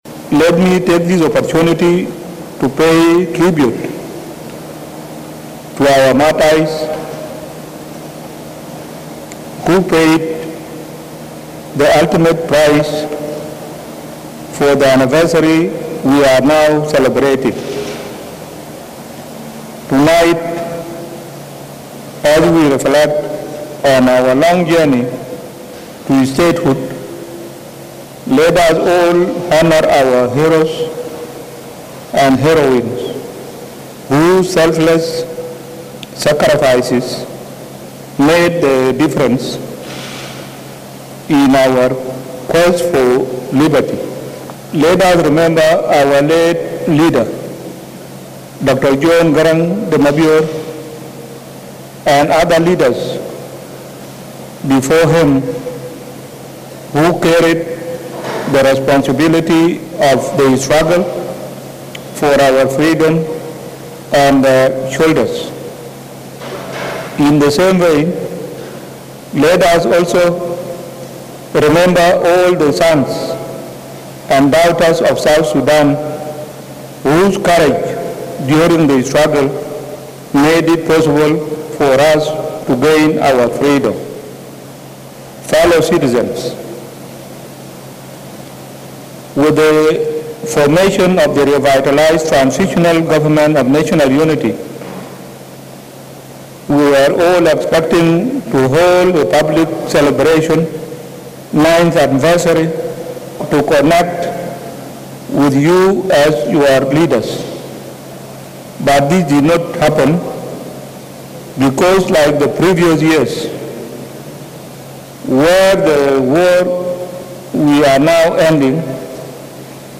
FULL: President Salva Kiir’s Independence Day message